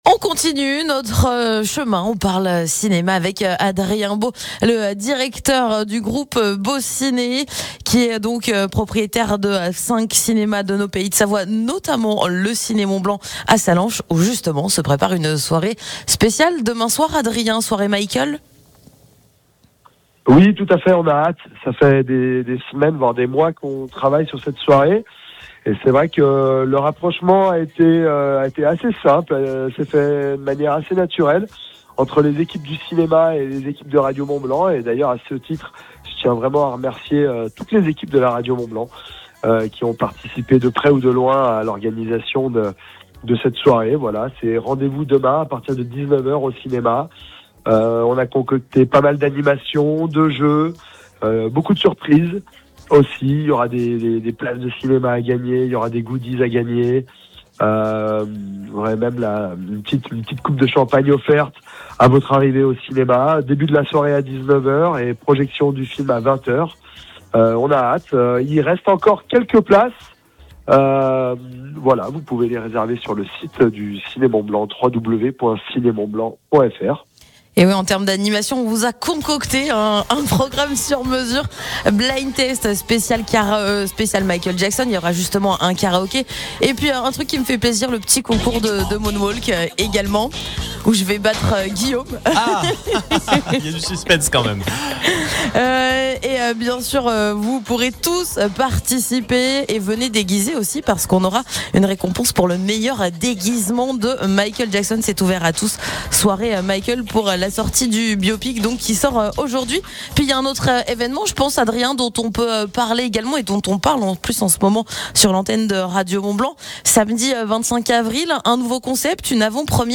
Interview part 3